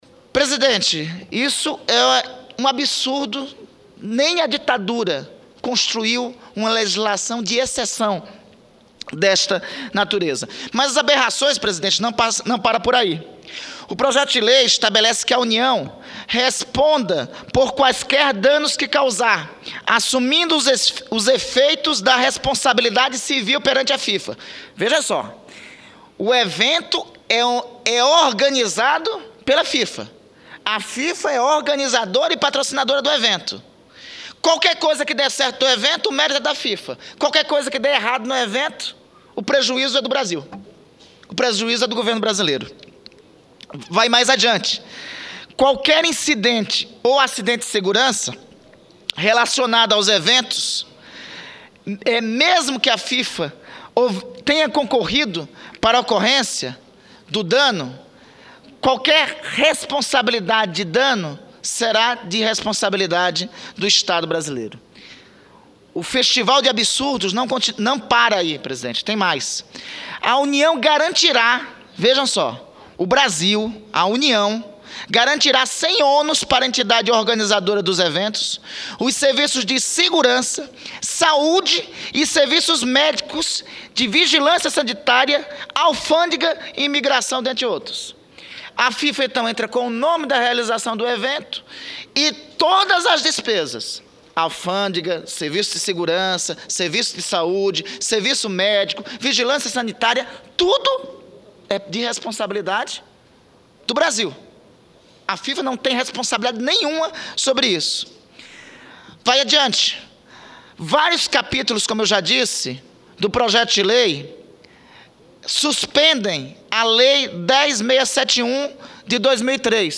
Discurso do senador Randolfe Rodrigues (2)